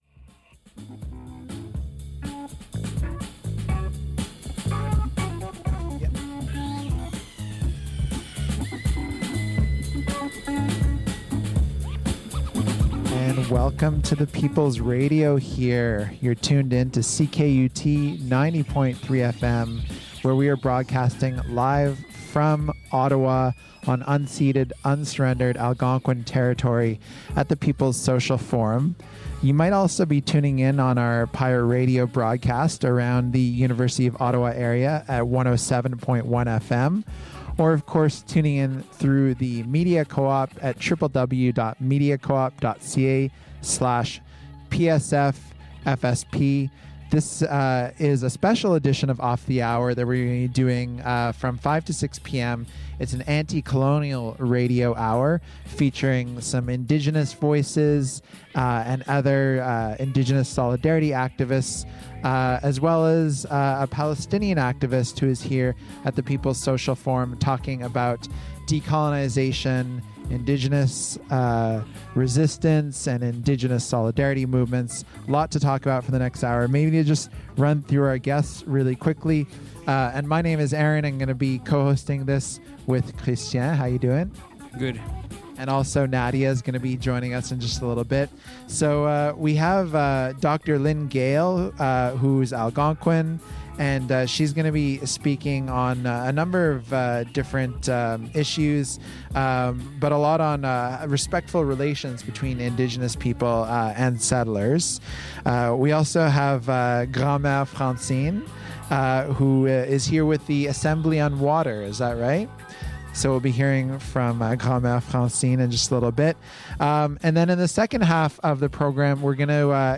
CKUT news show on location at the Peoples' Social Forum!